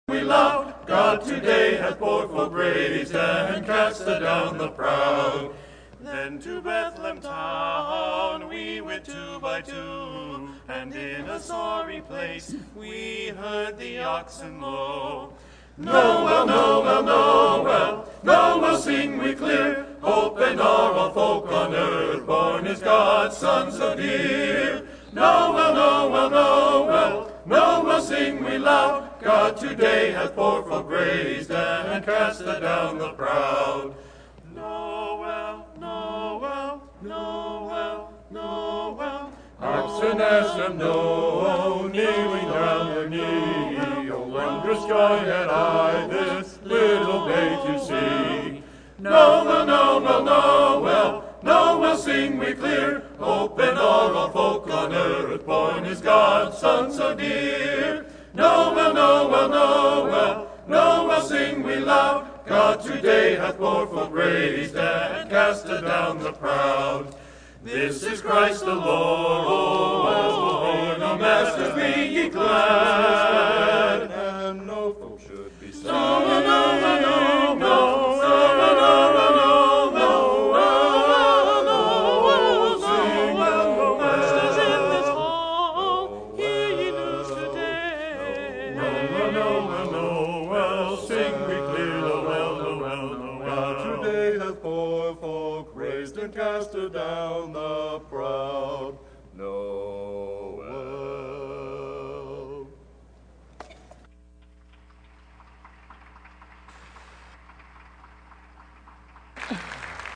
In this audio I am alto, and the recording doesn’t start at the beginning.